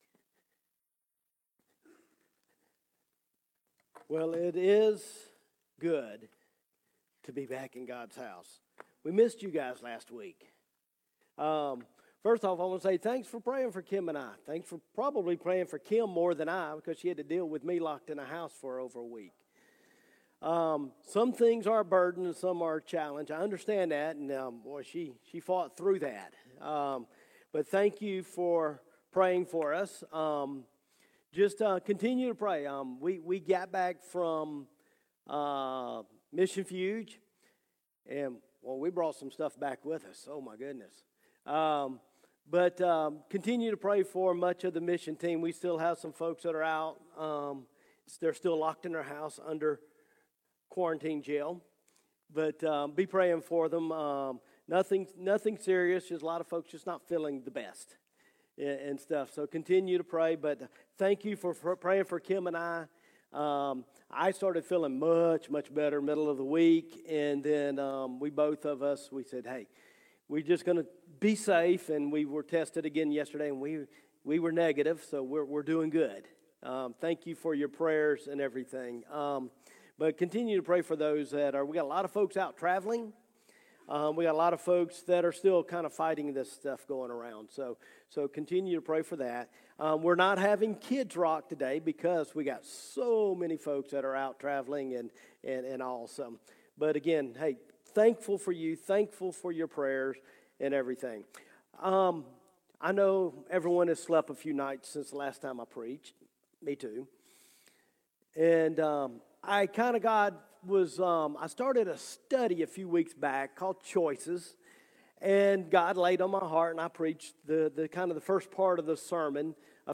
Sermons | Eastside Baptist Church